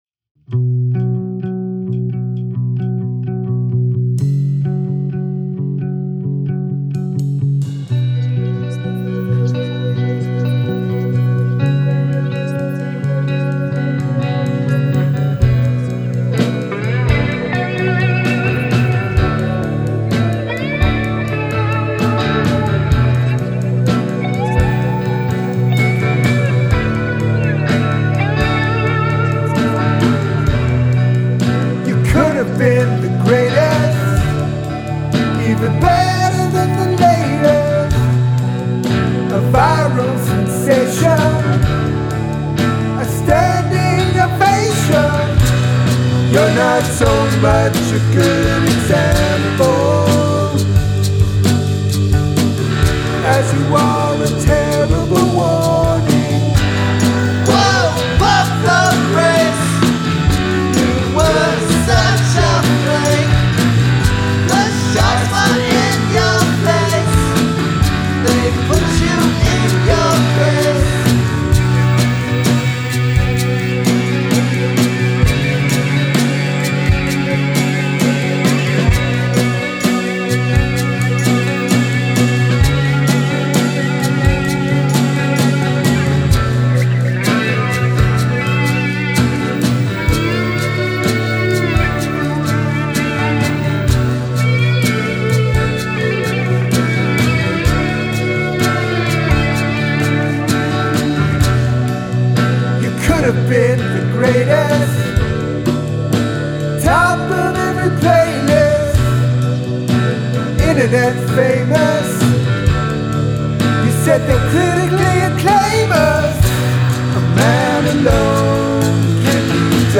Diss Track